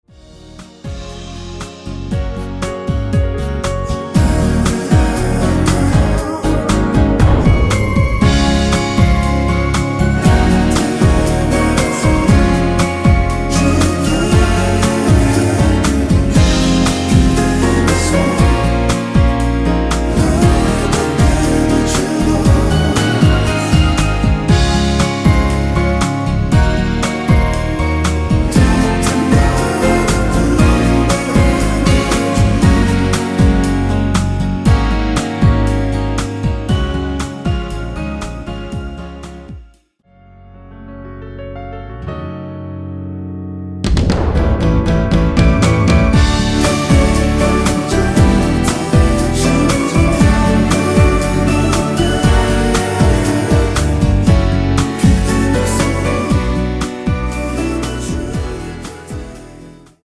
원키에서(-2)내린 코러스 포함된 MR 입니다.(미리듣기 참조)
Db
앞부분30초, 뒷부분30초씩 편집해서 올려 드리고 있습니다.